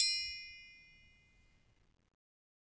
Triangle6-Hit_v1_rr2_Sum.wav